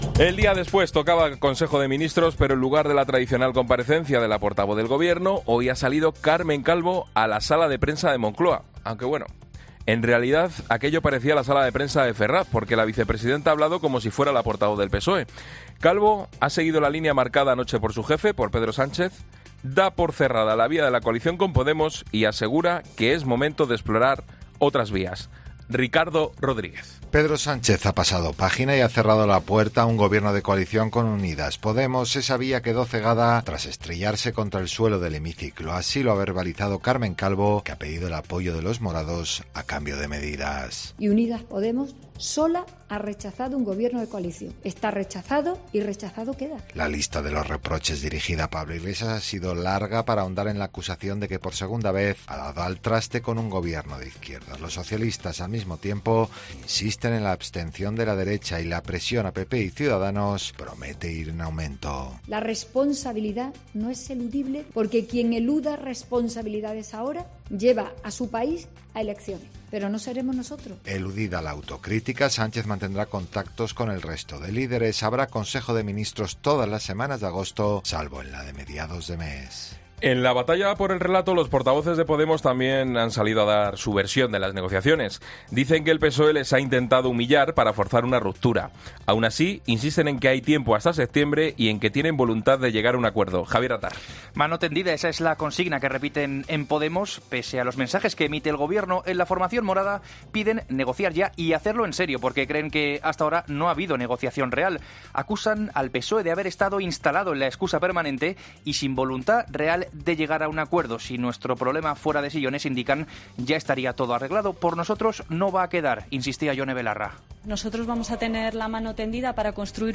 Boletín de noticias Cope del 26 de julio a las 19.00 horas